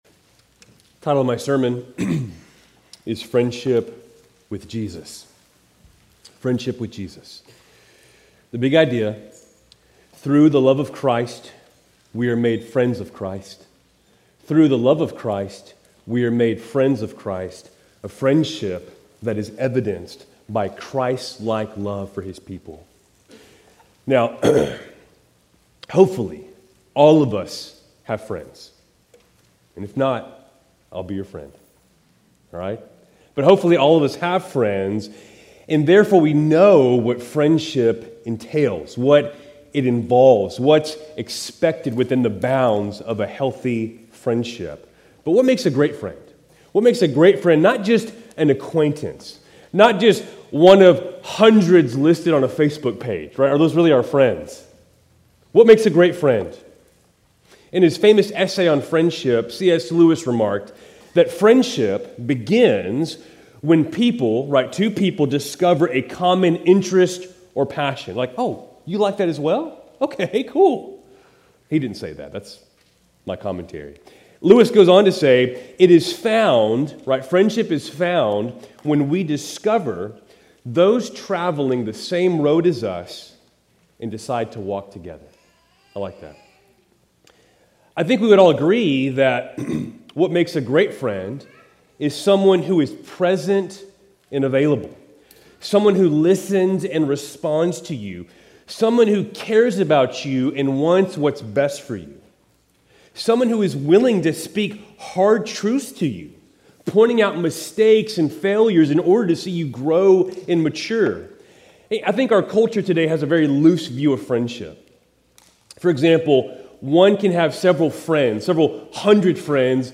Keltys Worship Service, August 24, 2025